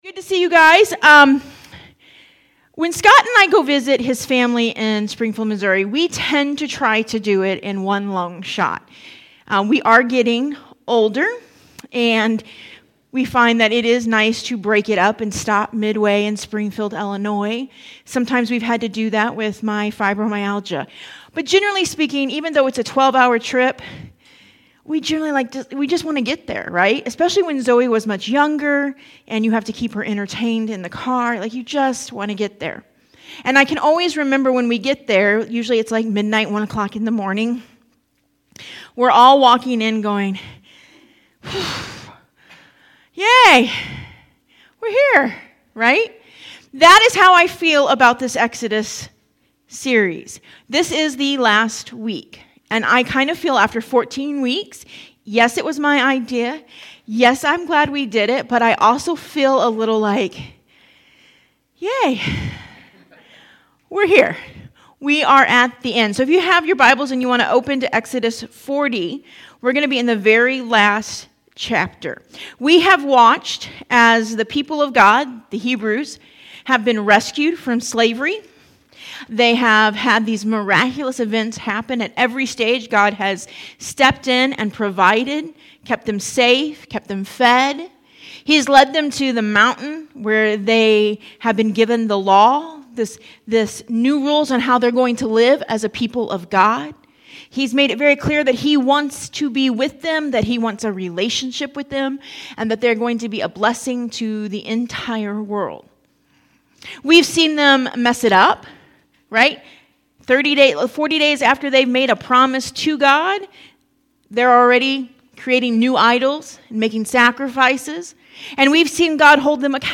Sermons | Compassion Church